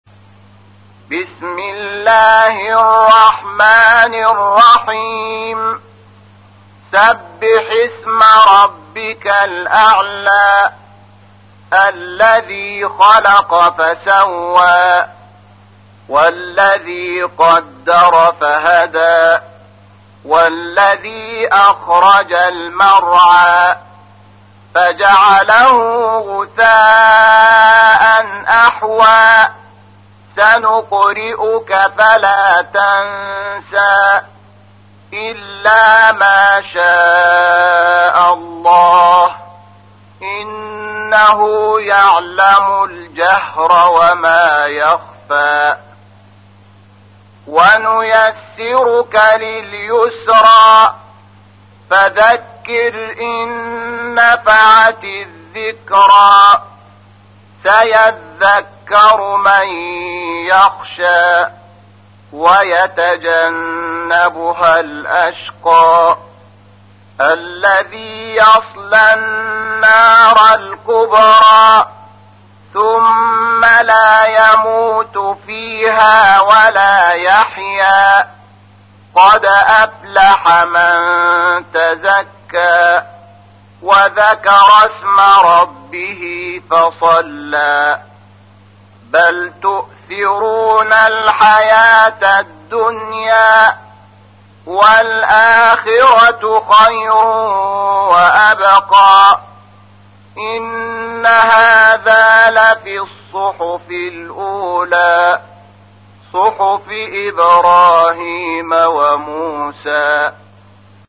تحميل : 87. سورة الأعلى / القارئ شحات محمد انور / القرآن الكريم / موقع يا حسين